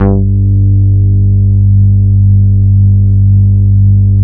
22 BASS   -L.wav